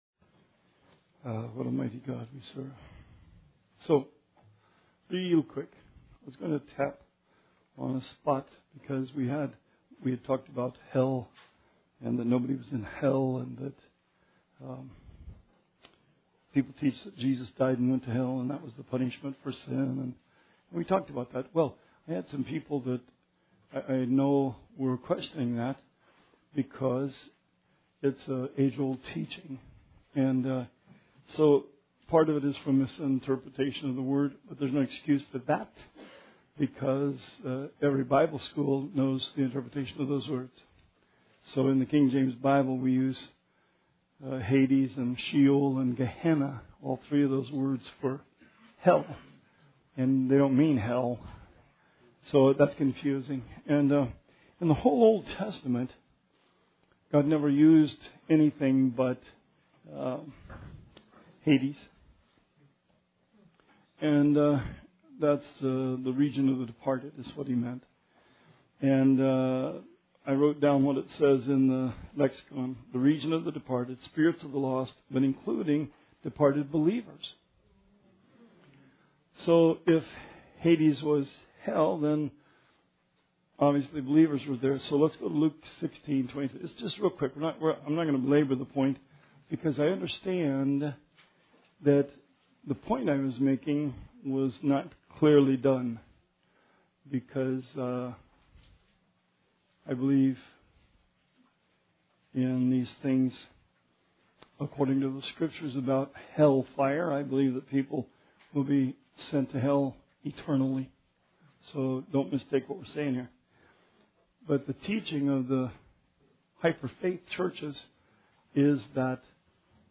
Bible Study 10/26/16